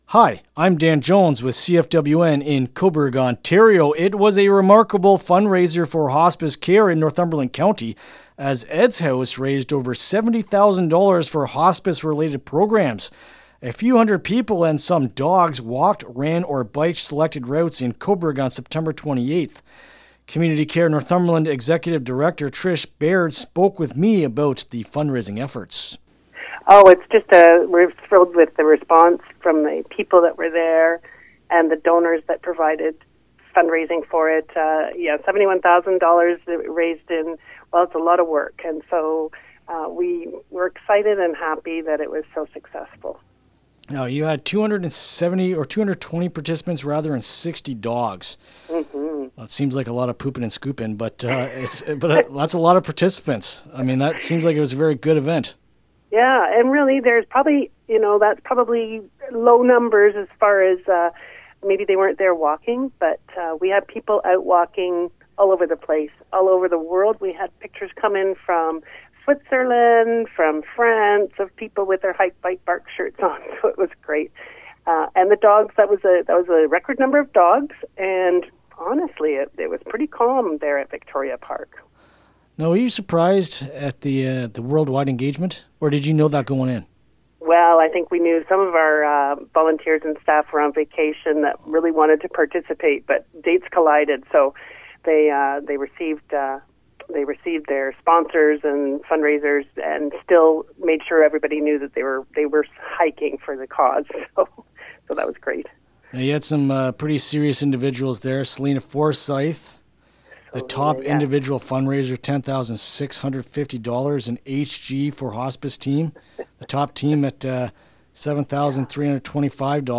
Eds-House-Interview-LJI.mp3